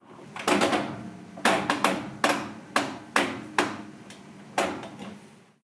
Controlador de los programas de una lavadora
Sonidos: Agua
Sonidos: Hogar